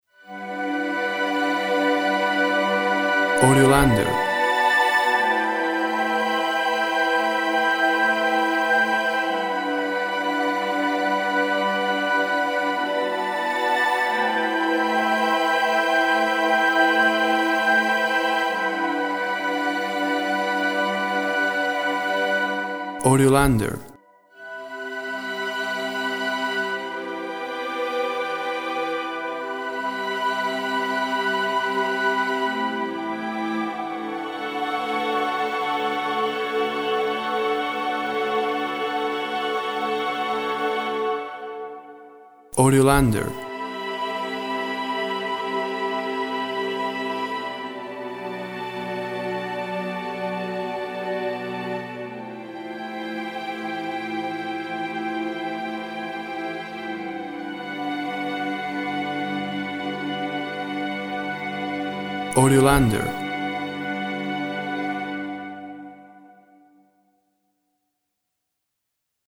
Strings and winds play as if in a dream.
Tempo (BPM) 58